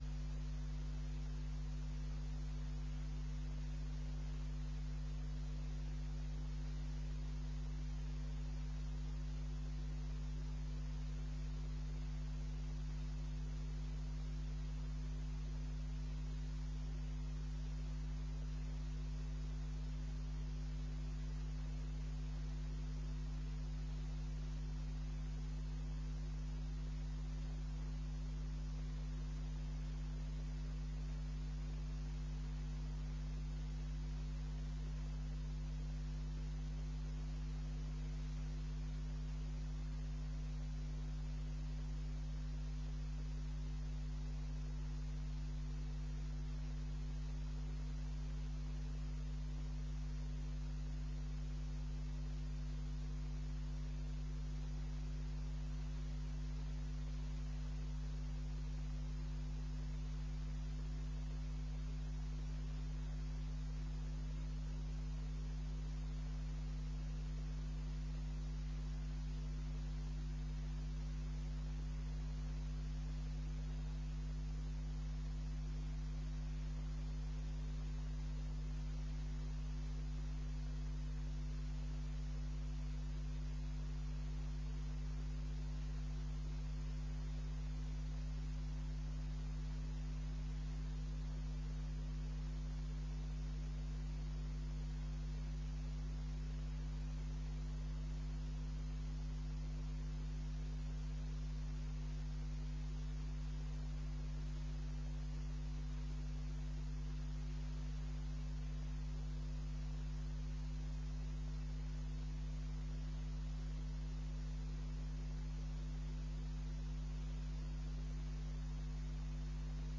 Gemeenteraad 26 mei 2009 20:00:00, Gemeente Tynaarlo
Download de volledige audio van deze vergadering
Locatie: Raadszaal